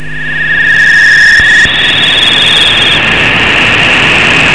UFO2.mp3